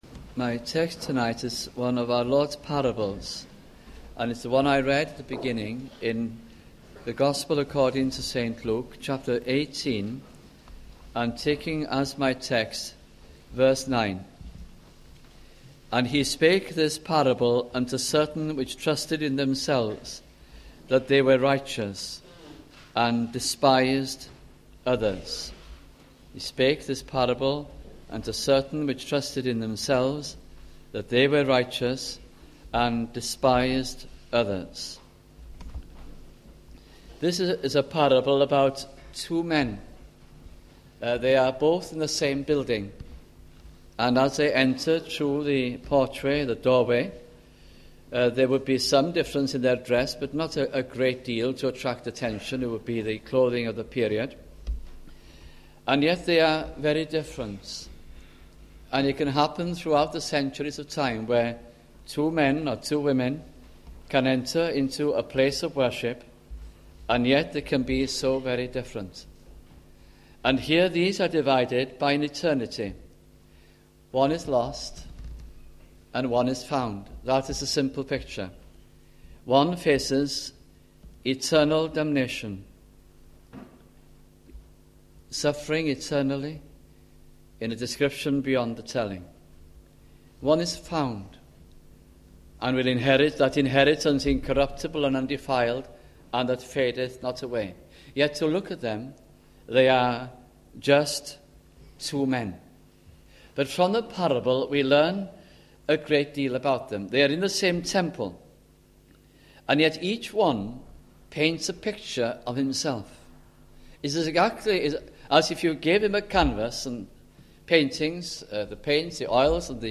» Luke Gospel Sermons